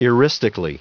Prononciation du mot eristically en anglais (fichier audio)
Prononciation du mot : eristically